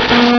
pokeemerald / sound / direct_sound_samples / cries / pupitar.aif